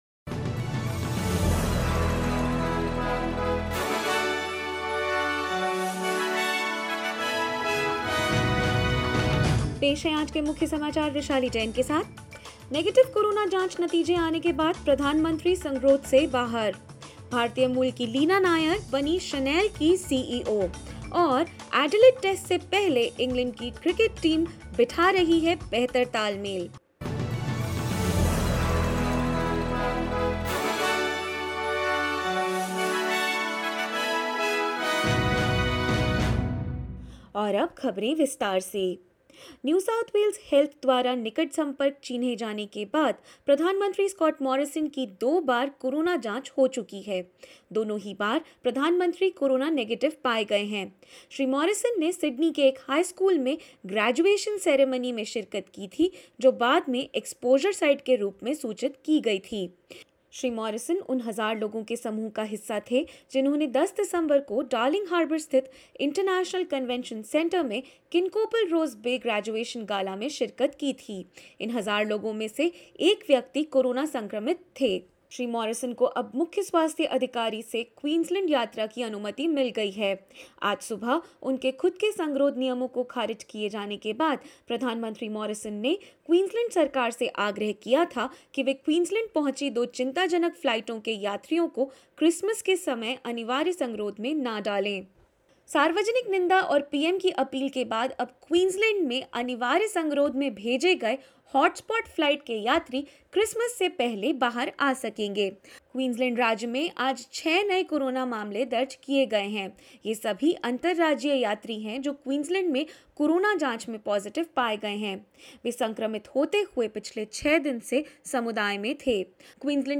In this latest SBS Hindi news bulletin of Australia and India: Australia sees an influx of travellers as borders reopen for skilled migrants, students and working holiday makers; WTO rules in favour of Australia and others against India in a 2019 sugar subsidies appeal and more.